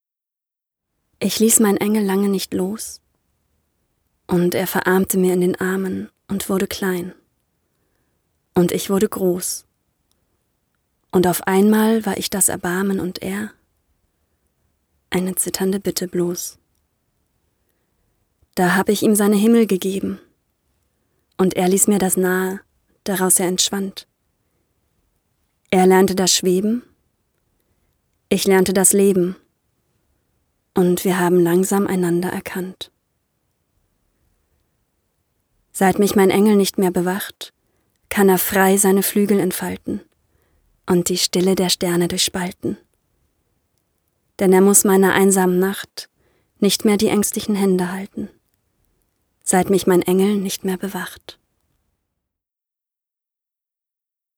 Authentische, von jung frisch frech über verträumt sinnlich bis dynamisch seriöse Stimme.
Sprechprobe: Sonstiges (Muttersprache):
Female voice over artist German/English. Authentic voice, from young and fresh to sleepy sensual and dynamic respectable.